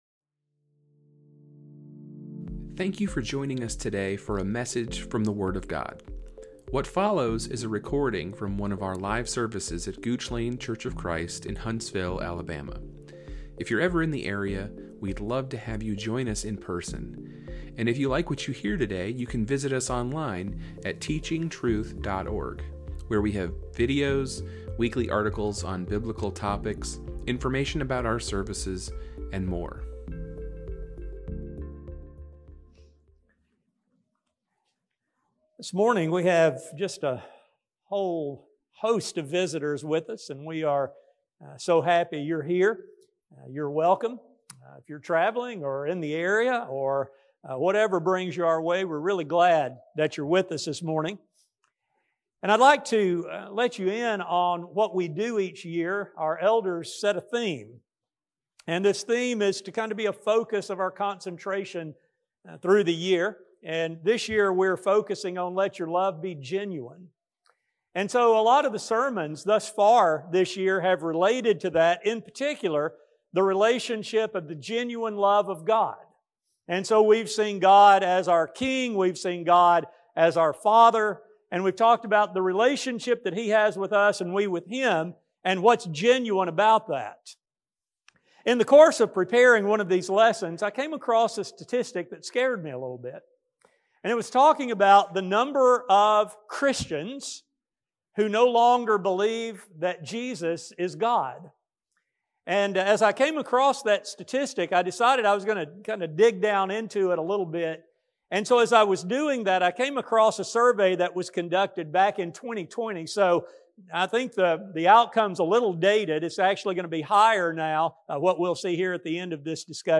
This study will examine how the inspired texts deal with Jesus and what can and should be believed about the Savior. A sermon